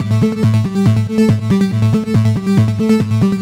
Index of /musicradar/french-house-chillout-samples/140bpm/Instruments
FHC_Arp B_140-A.wav